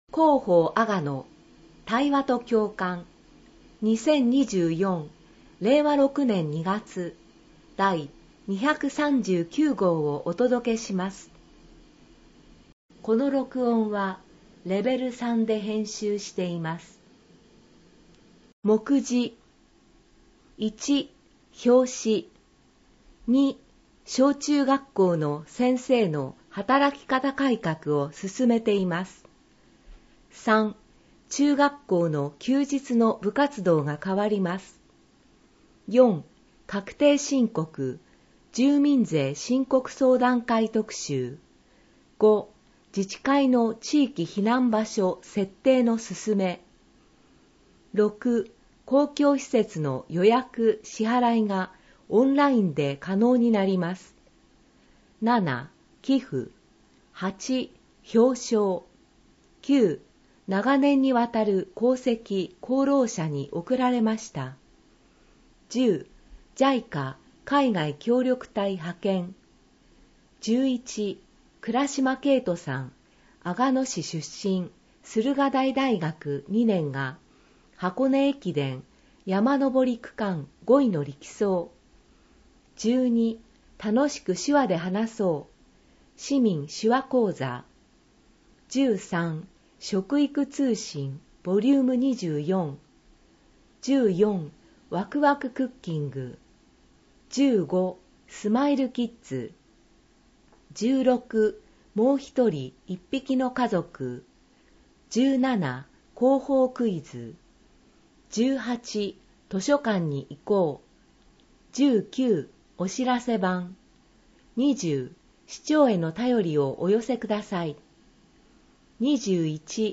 音声版広報あがの令和6年2月号
市では、視覚に障がいのある方向けに、ボランティア団体「うぐいす会」の皆さんのご協力により、広報あがのを音声訳したCDを作成し、希望する方に配付しています。